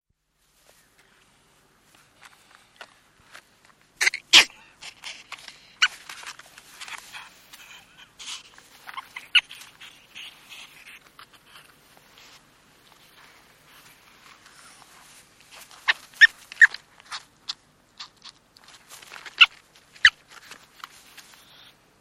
На этой странице собраны разнообразные звуки хорьков: от игривого попискивания до довольного урчания.
Звуки лесного хорька в естественной среде